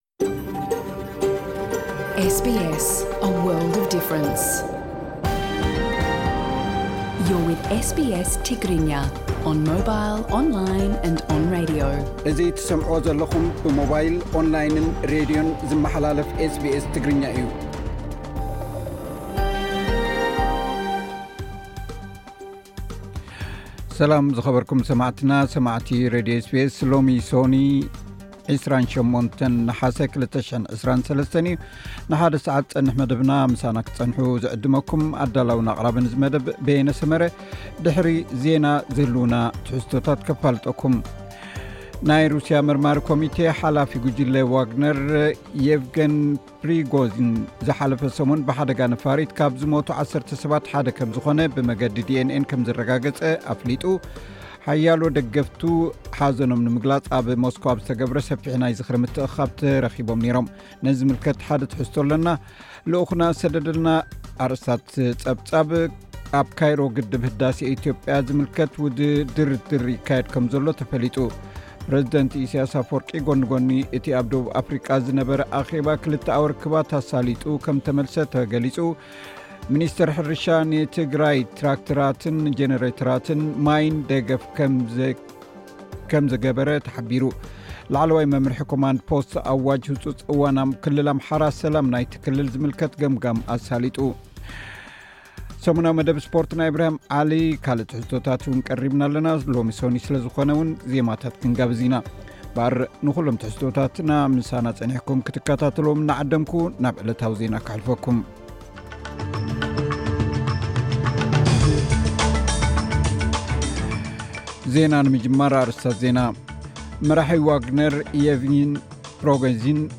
ዕለታዊ ዜና ኤስ ቢ ኤስ ትግርኛ (28 ነሓሰ 2023)